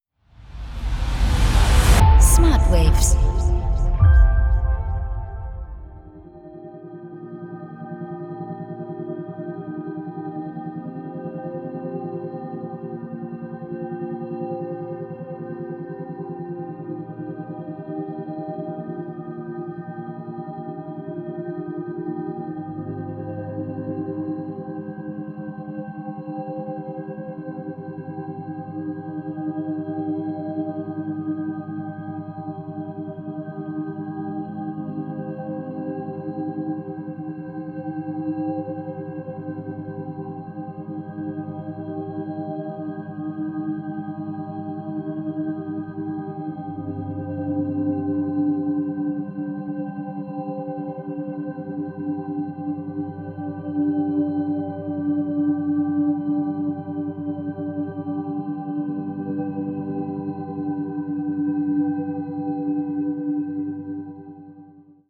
tiefe und entspannende Hintergrundmusik
• Methode: Binaurale Beats
• Frequenz: 5,5-7 Hertz